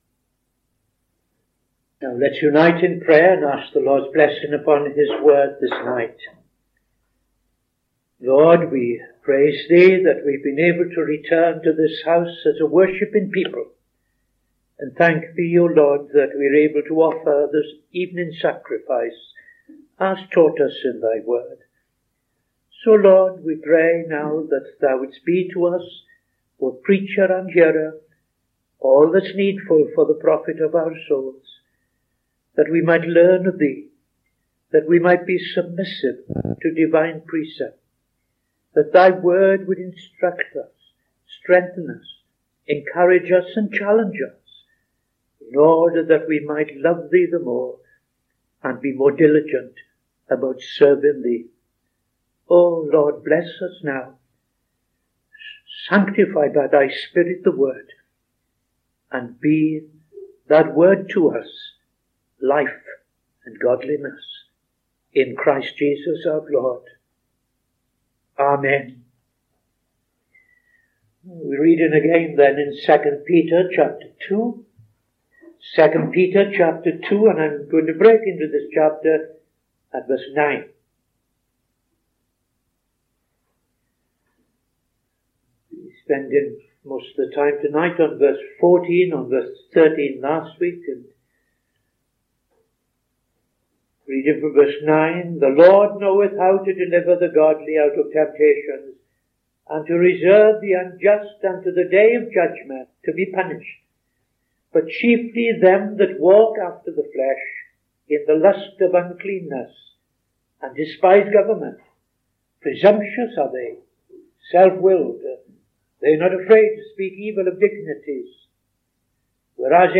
Opening Prayer and Reading II Peter 2:9-16